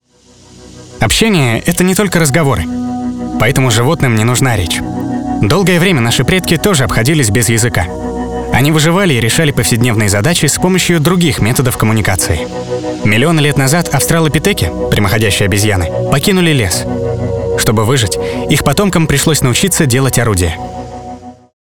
Закадровый текст
Муж, Закадровый текст
SE X1, Long VoiceMaster, Scarlett 2i2